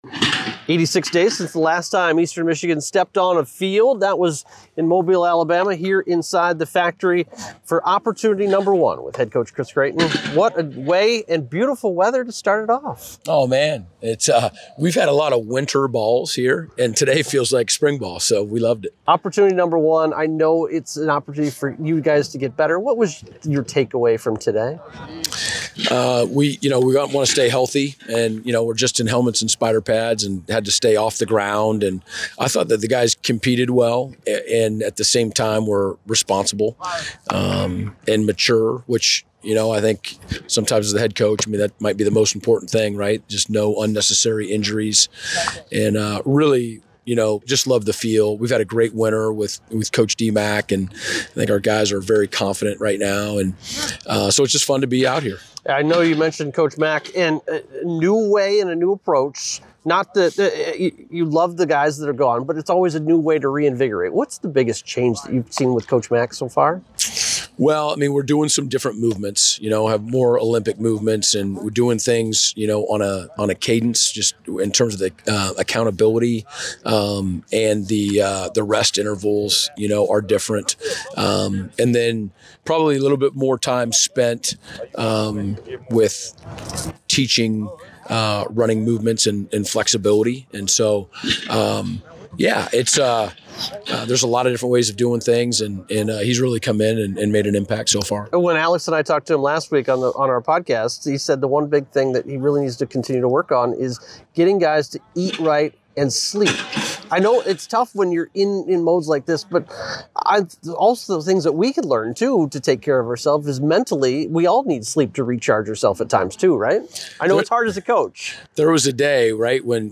Post-practice Audio